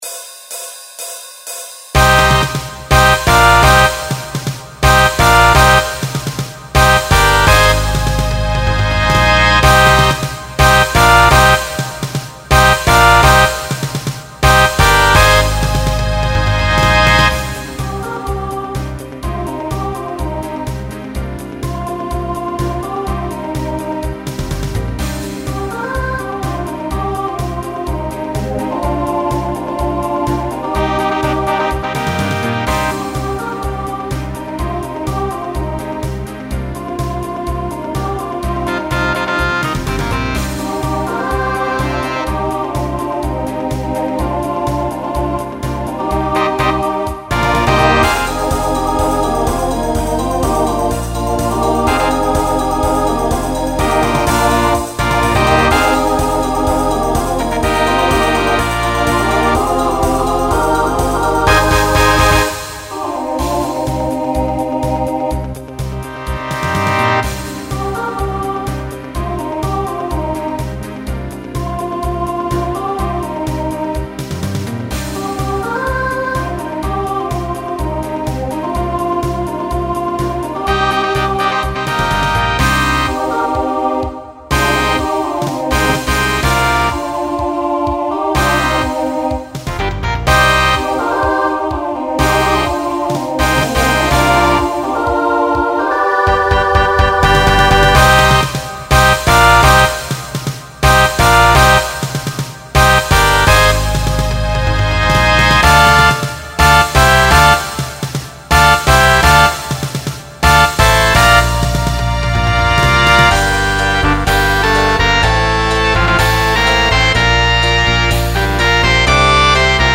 Genre Rock
Instrumental combo
Opener Voicing SSA